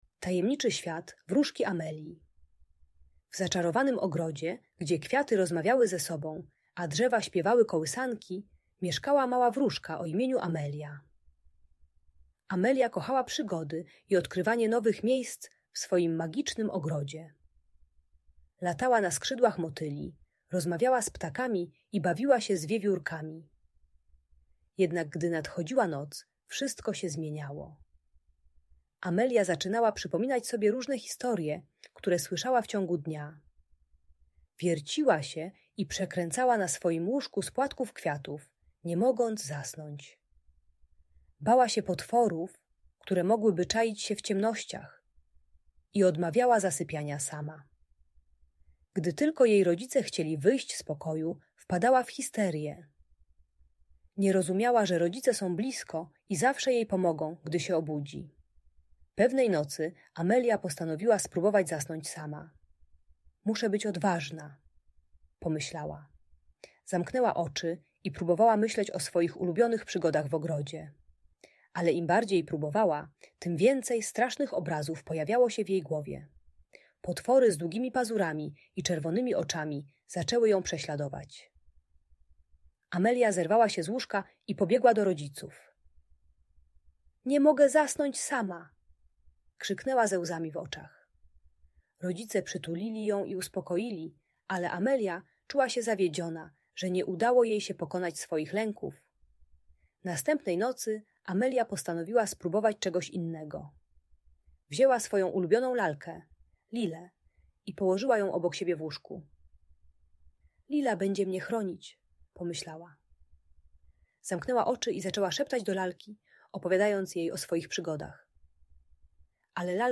Tajemniczy Świat Wróżki Amelii - Magiczna Opowieść - Audiobajka